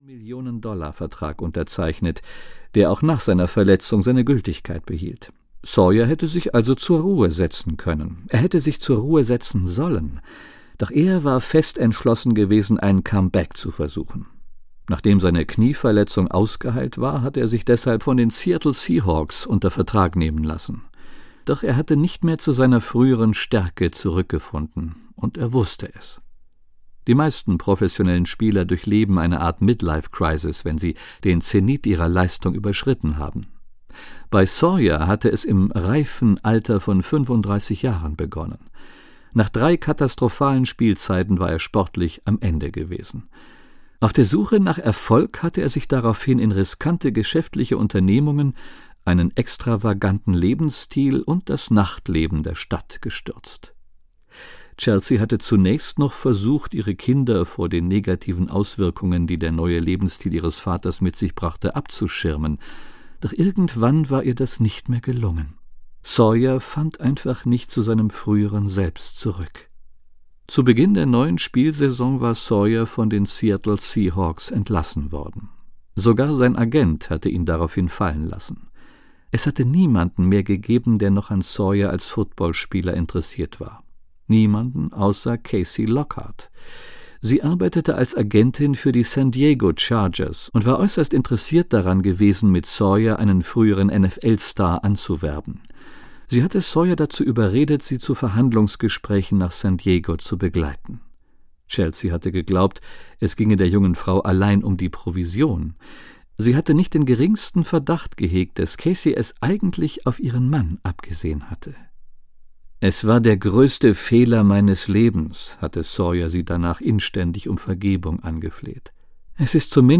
Das Café zwischen Himmel und Erde - Max Lucado - Hörbuch